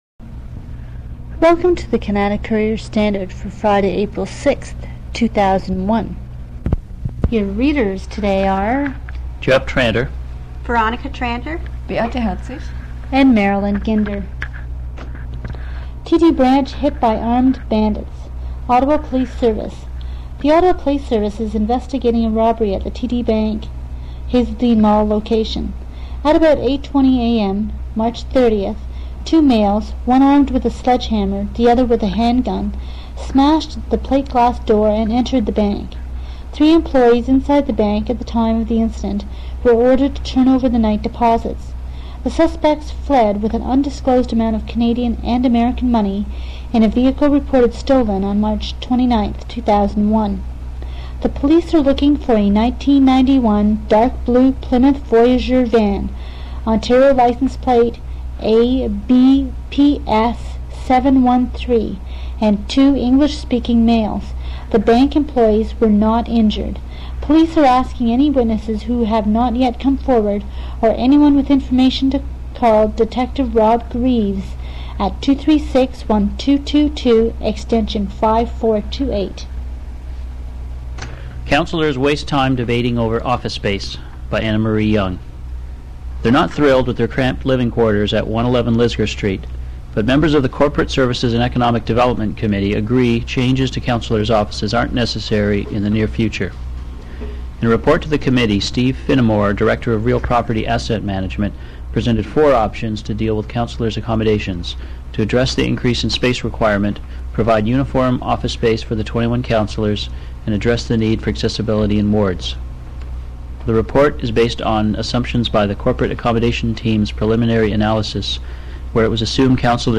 Sample portion of a weekly reading (MP3 format, 2.4MB) CommuniTape Instructions (Microsoft Word 97 format) Instructions For Readers (Microsoft Word 97 format)